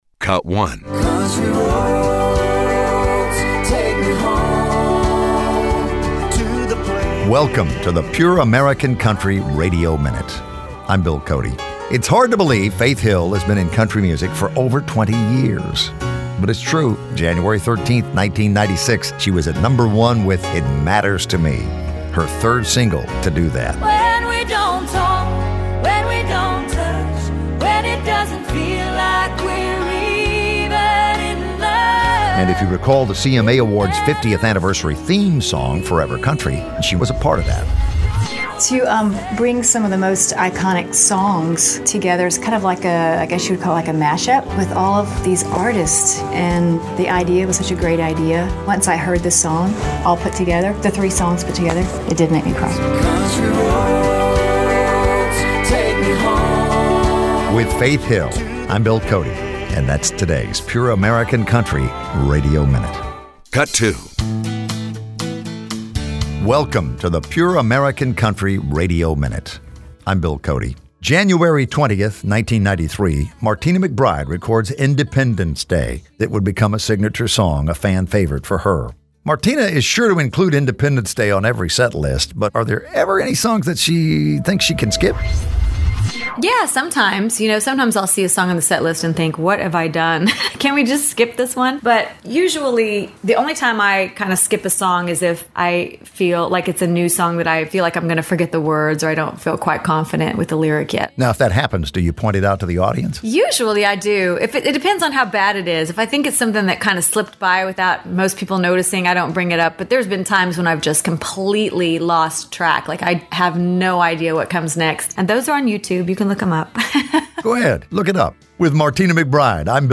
Add The PURE AMERICAN COUNTRY RADIO MINUTE for a bright, lively daily break.